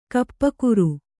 ♪ kappakuru